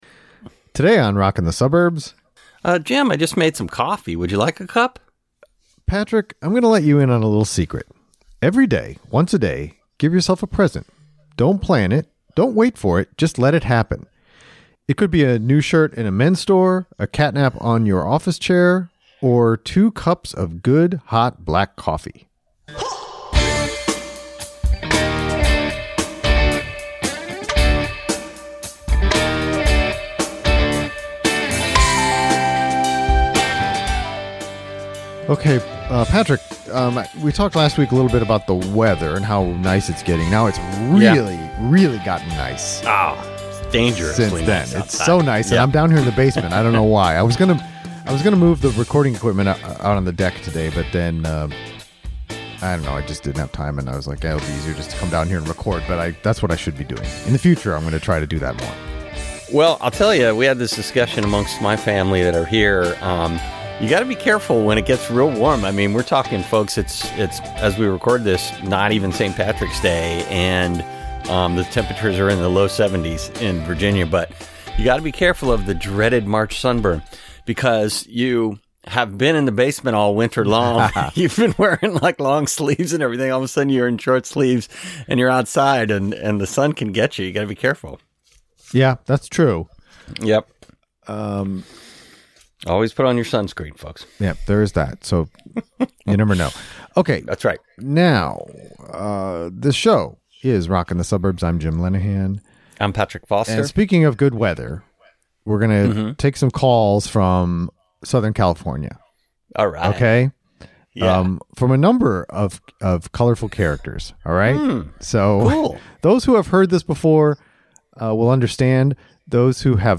We take a series of calls from some colorful characters in Southern California. They talk about new music they have recently discovered.